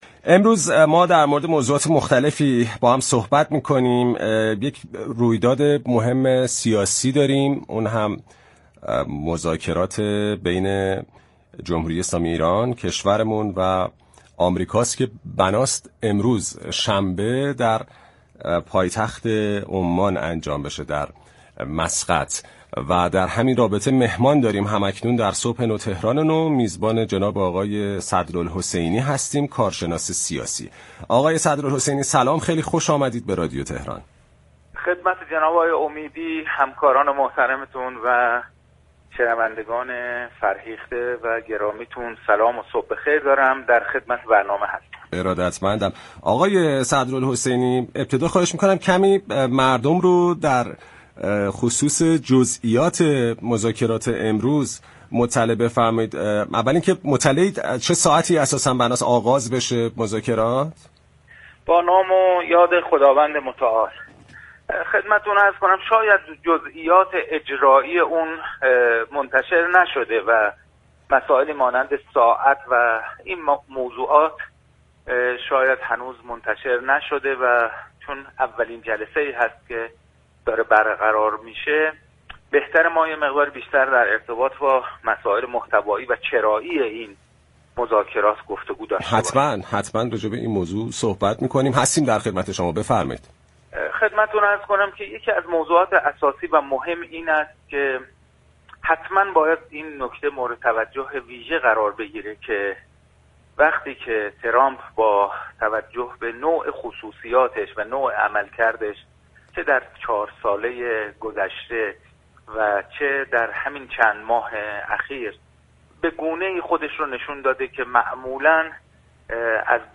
كارشناس سیاسی در گفت و گو با «صبح نو، تهران نو»